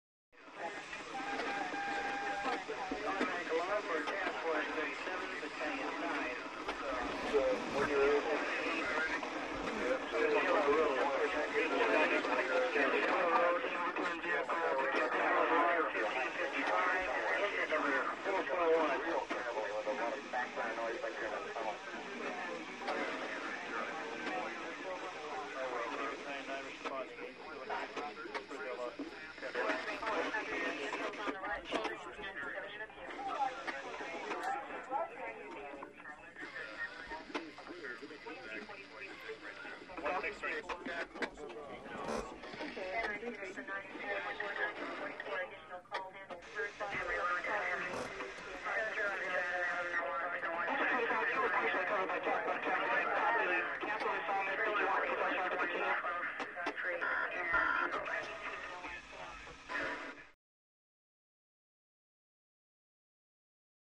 Dispatch Room - Radio Chatter & Static